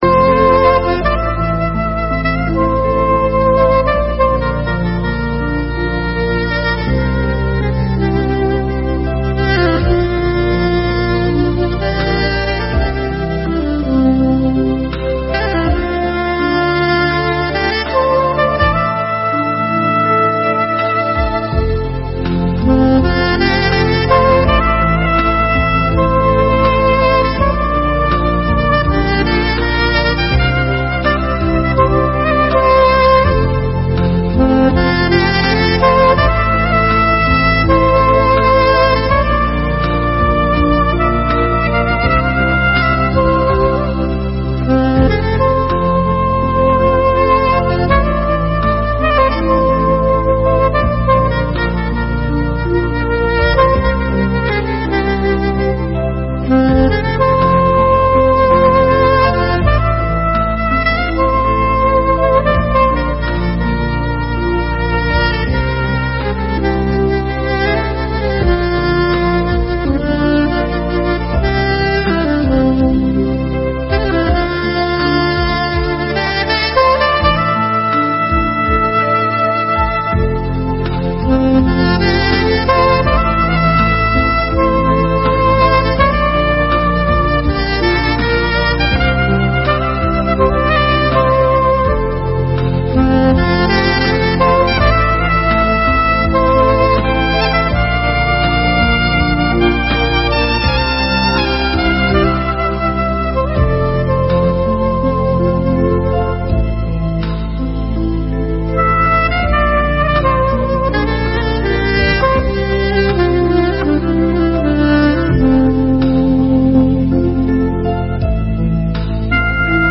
01/01/2017 - Sessão de Posse